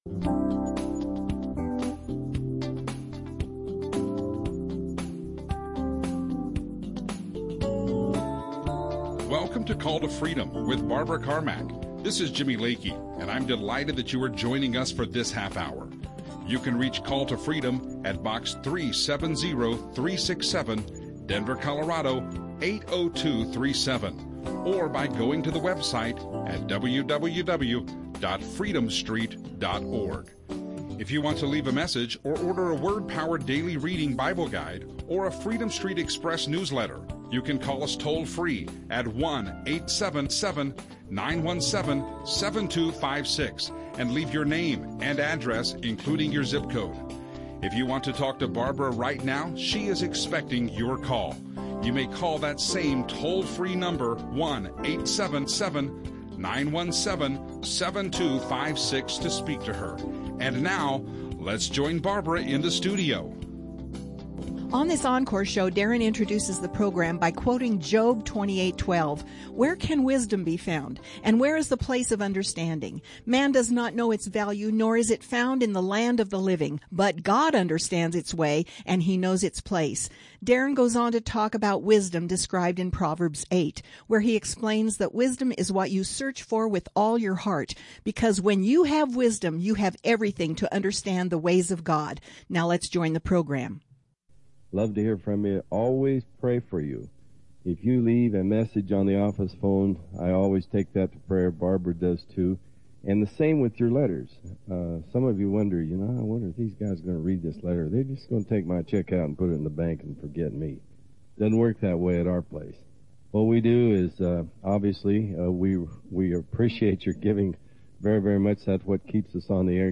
Audio teachings
Christian radio